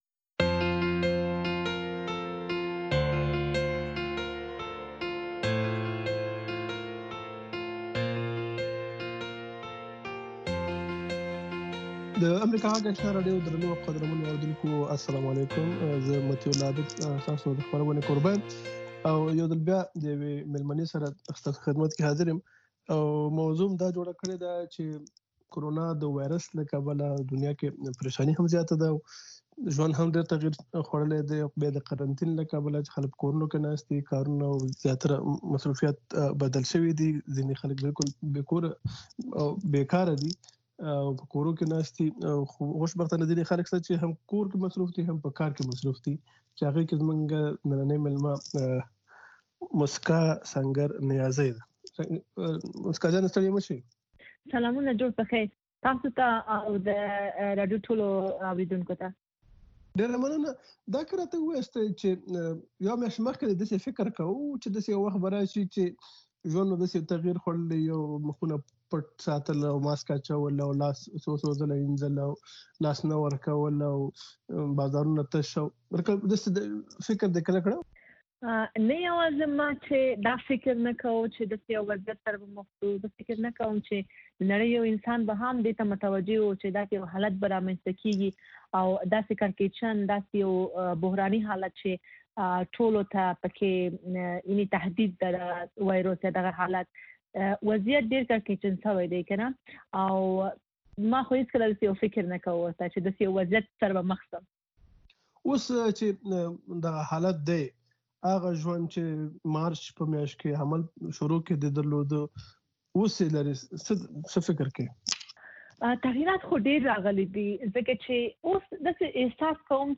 خبرې اترې
په دغې خپرونه کې د روانو چارو پر مهمو مسایلو باندې له اوریدونکو او میلمنو سره خبرې کیږي.